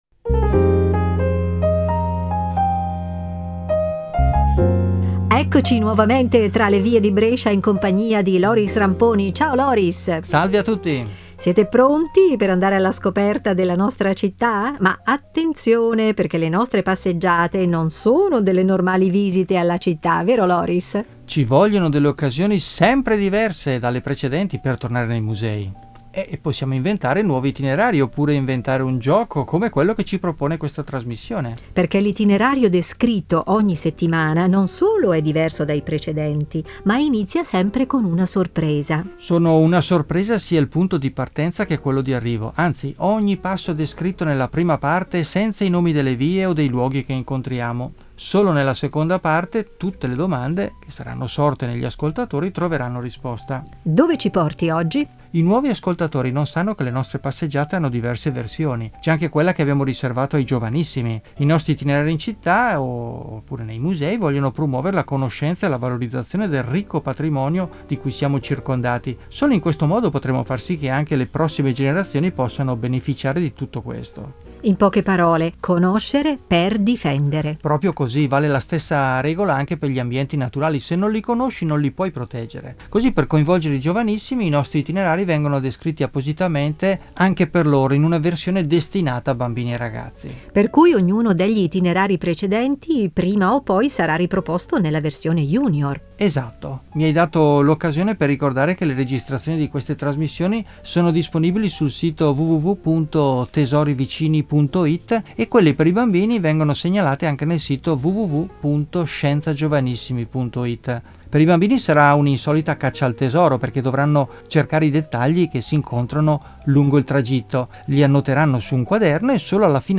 audio-guida junior e itinerari junior per passeggiare tra le vie di Brescia